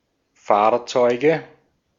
Ääntäminen
Ääntäminen Tuntematon aksentti: IPA: /ˈfaːɐ̯t͡sɔɪ̯ɡə/ Haettu sana löytyi näillä lähdekielillä: saksa Käännöksiä ei löytynyt valitulle kohdekielelle. Fahrzeuge on sanan Fahrzeug monikko.